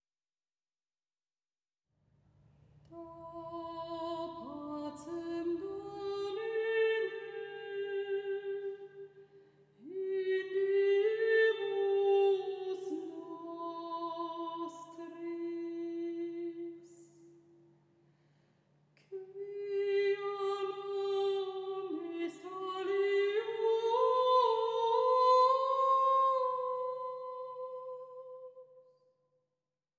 Zwei großbesetzte Werke für Soli, Chor und Orchester